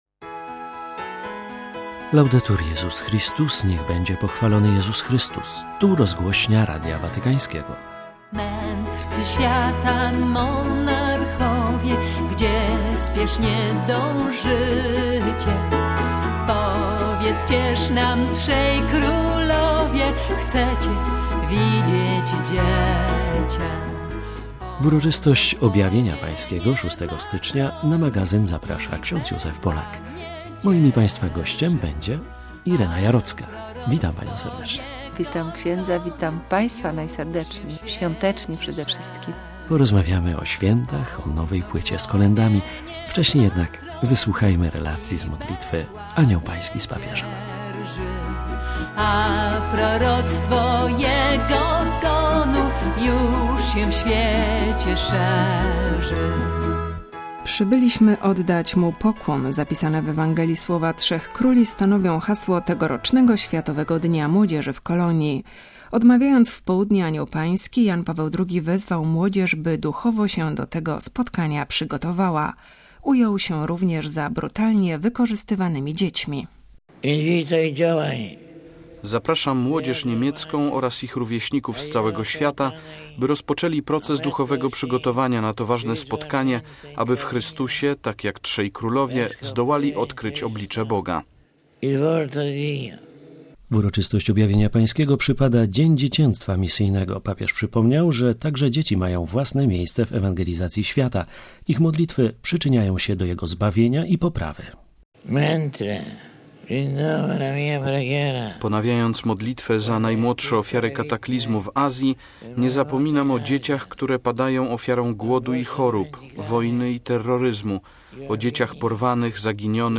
W Uroczystość Objawienia Pańskiego gościem programu będzie Irena Jarocka
Nadamy także obszerną relację z modlitwy Anioł Pański z papieżem